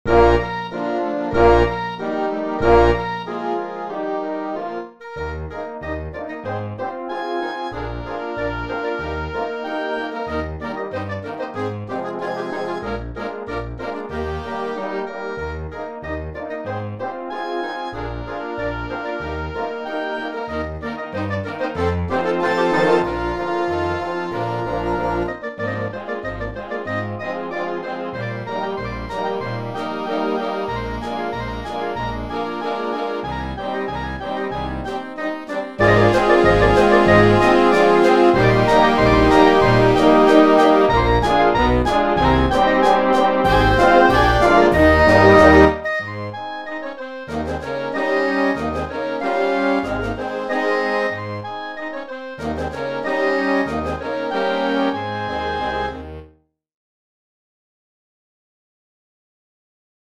Polka française für großes Blasorchester